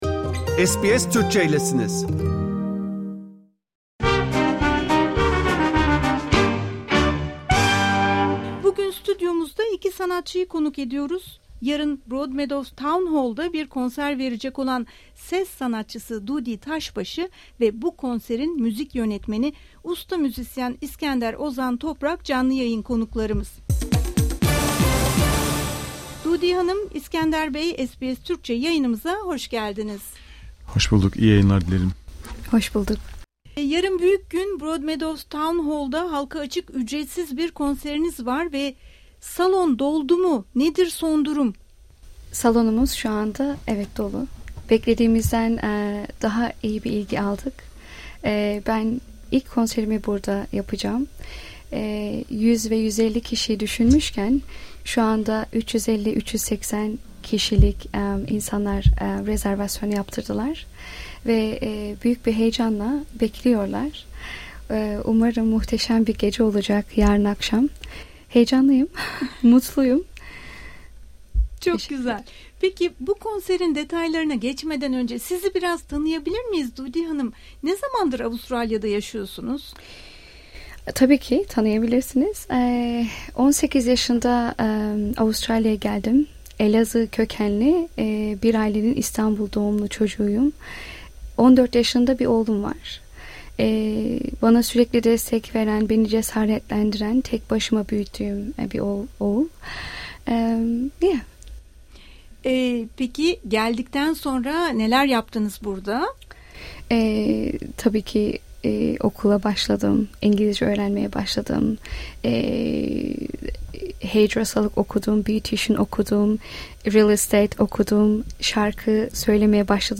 canlı yayın konuğumuz oldu
stüdyo konuğumuzdu.